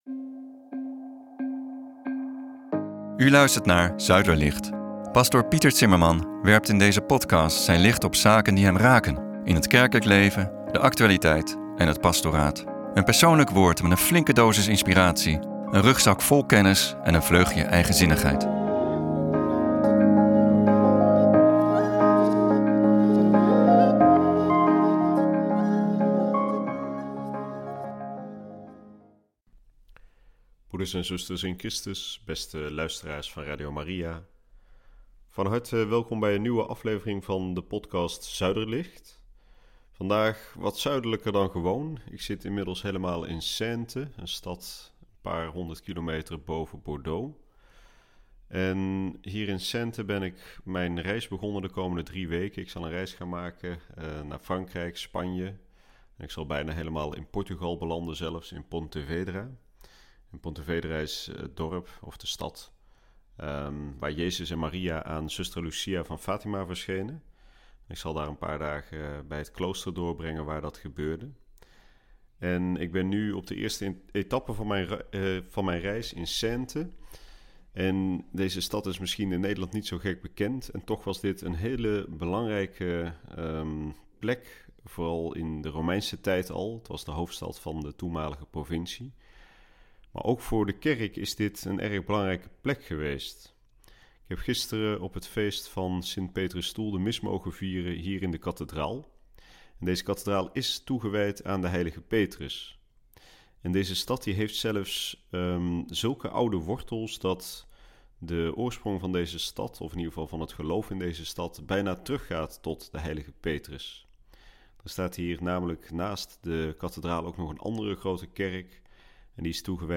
brengt deze podcast vanuit Frankijk op doorreis naar Portugal o.a. naar Ponte Vedra. Een podcast over de structuur van de Katholieke kerk.&nbsp